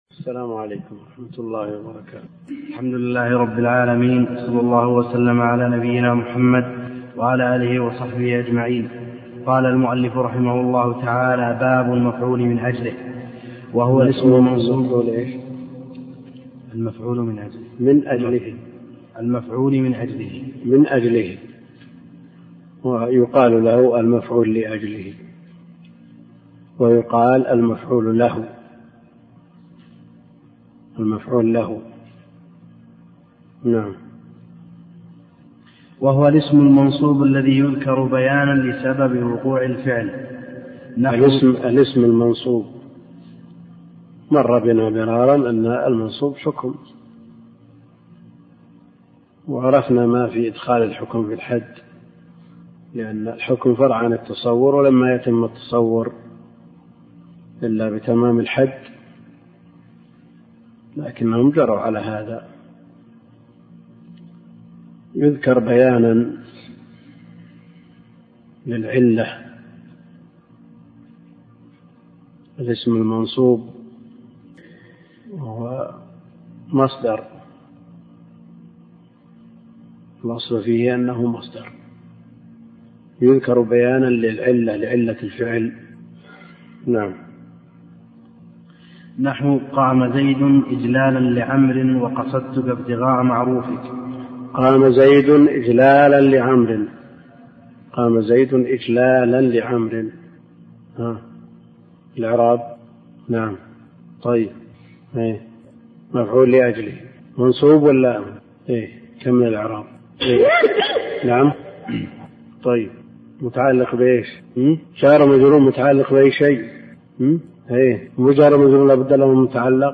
أرشيف الإسلام - ~ أرشيف صوتي لدروس وخطب ومحاضرات الشيخ عبد الكريم الخضير